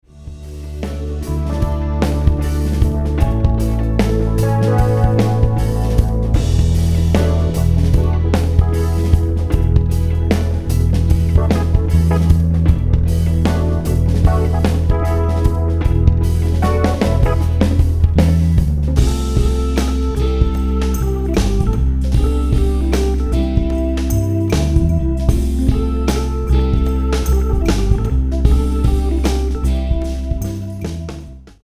76 BPM